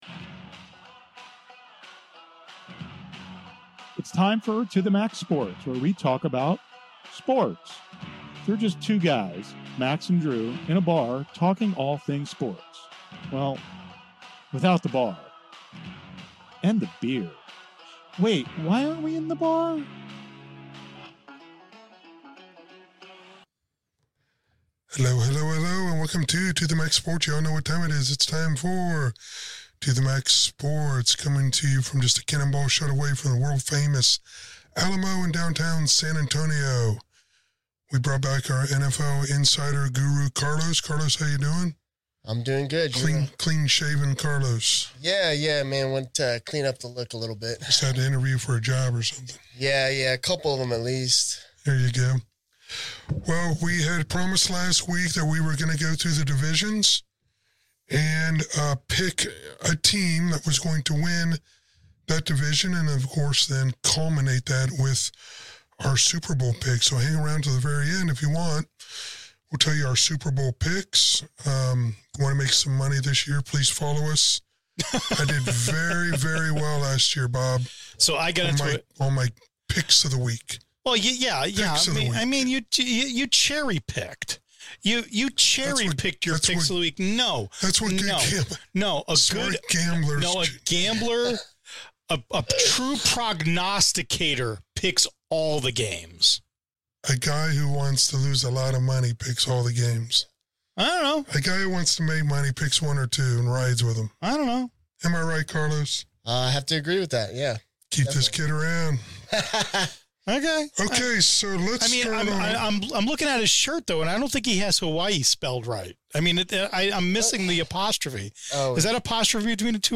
To the Max Sports is a weekly sports show where we do on camera what we all do in the bar - talk and argue about sports.